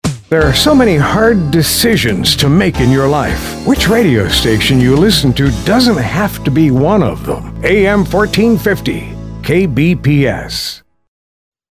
They have in the past, graciously donated their services to provide KBPS with on-air imaging.